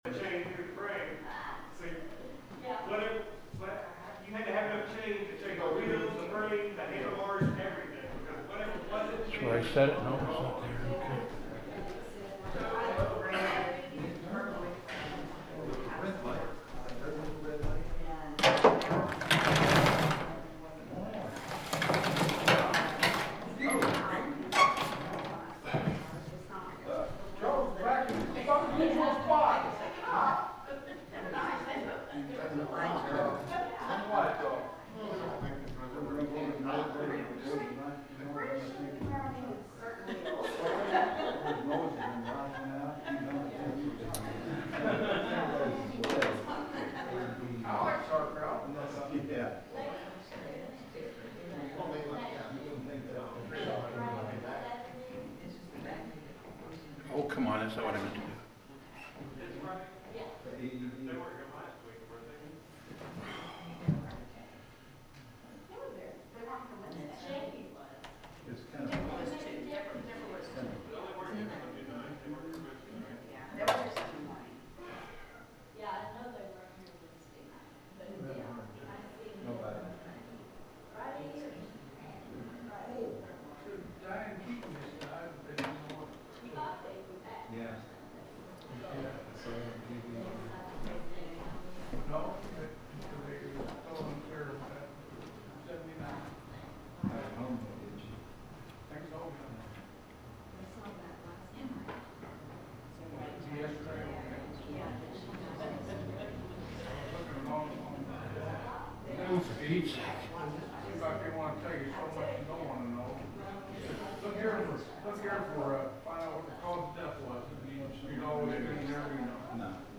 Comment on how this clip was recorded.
The sermon is from our live stream on 10/12/2025